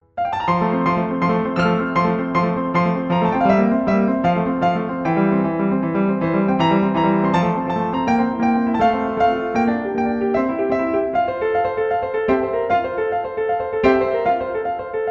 例えば第１楽章冒頭部中間、左手のアルペジオ的３連符が次への展開を仄めかす箇所をあげてみましょう。
さらにこの場合、２回、または４回ずつ、同じ３連符が連呼しています。